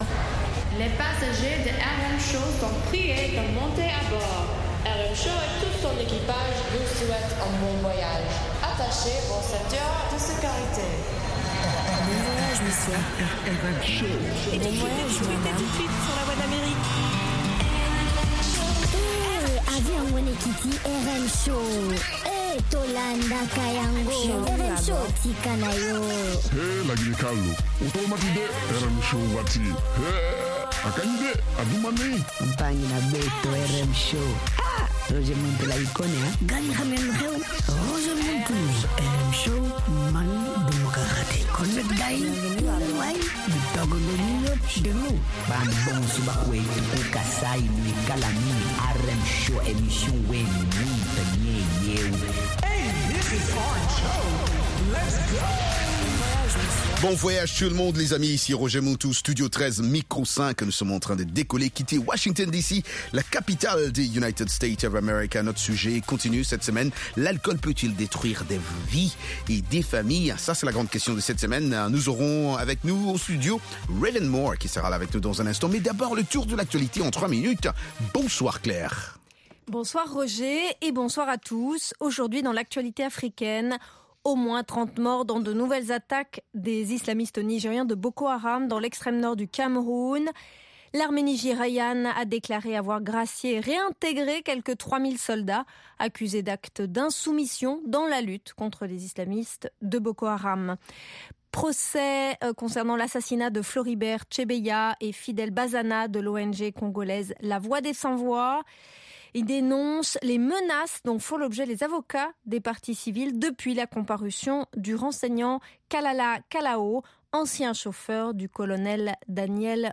propose notamment d'écouter de la musique africaine
interviews